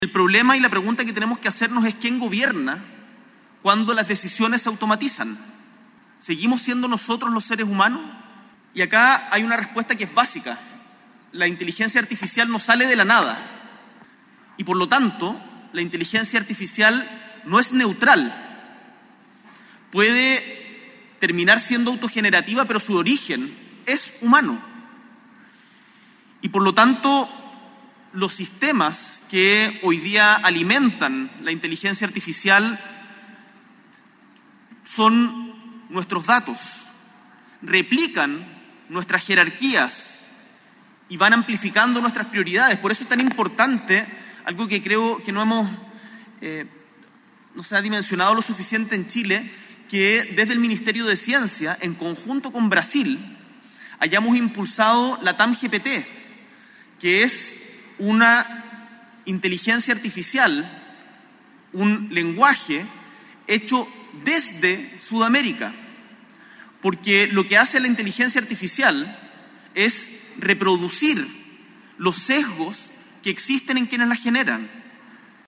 La inauguración del Congreso Futuro contó con la participación del Presidente Gabriel Boric y del Presidente Electo José Antonio Kast, entre otras autoridades.